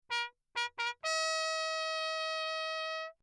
13 Spitzdämpfer (Straight Mutes) für Trompeten im Klangvergleich
Im Rahmen dieser Arbeit wurden verschiedene kurze Sequenzen zunächst ohne Dämpfer und dann mit dreizehn verschiedener Spitzdämpfer im reflexionsarmen Raum der mdw aufgenommen.
Drehventiltrompete
Signal 1
TRP-MUTE_Lechner_D_Wick_signal1.mp3